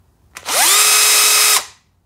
Звуки шуруповерта
Звук работы шуруповерта Bosch